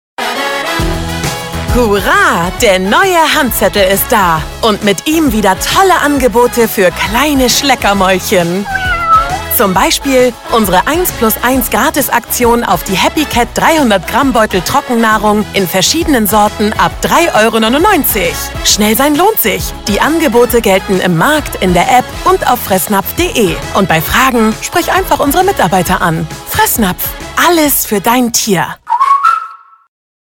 Werbung Axa